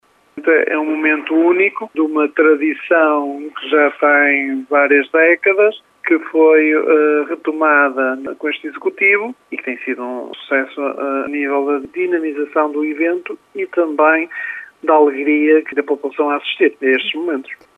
Além da exposição e venda de produtos da terra, o Dia da Freguesia de Lijó atrai muitas pessoas para o jogo de futebol entre padres e presidentes. Uma tradição de décadas, diz Filipe Oliveira